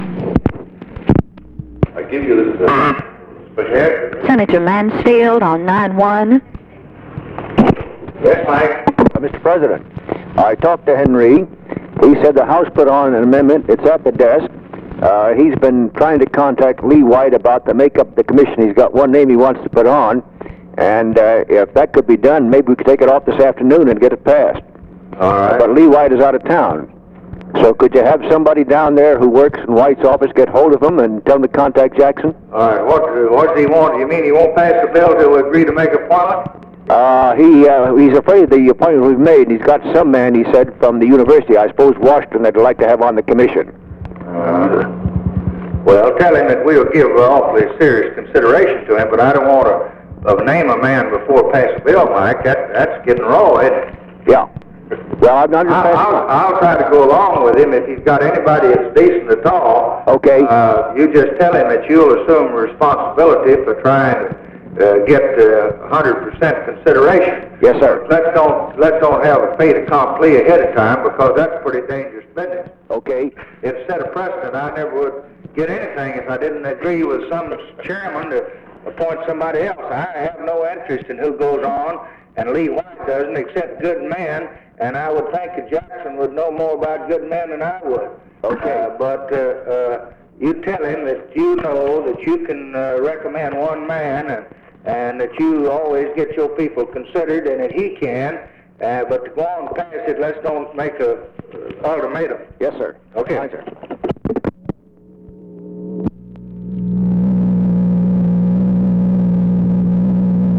Conversation with MIKE MANSFIELD, February 18, 1964
Secret White House Tapes